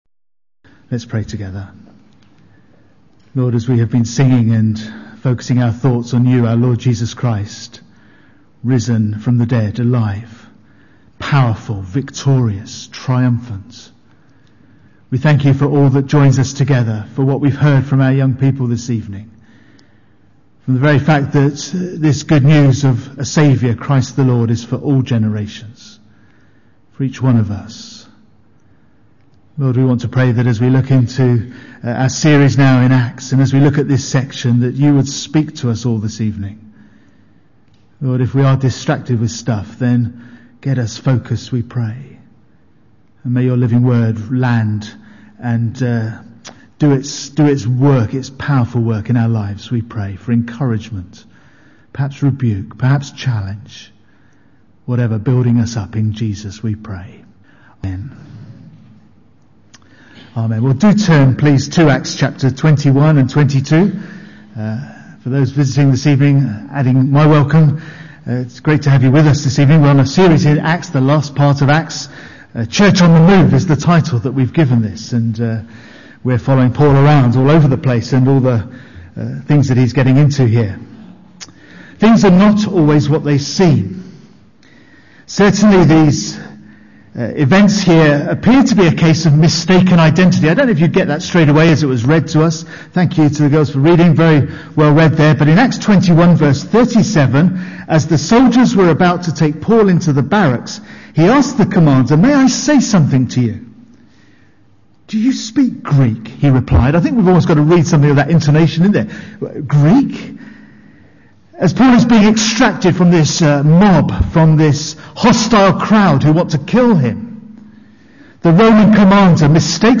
2011 Service type: Sunday PM Bible Text